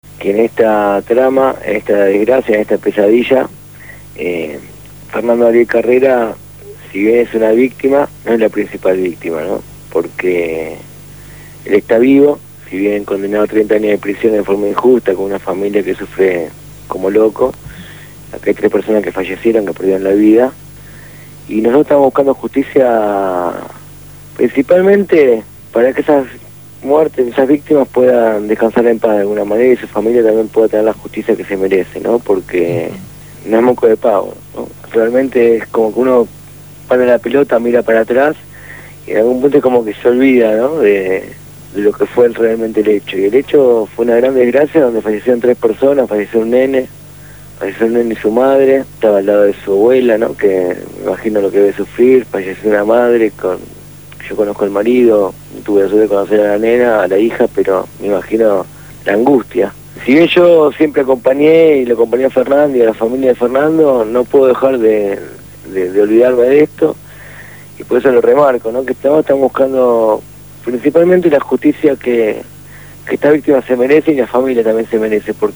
Hoy fue entrevistado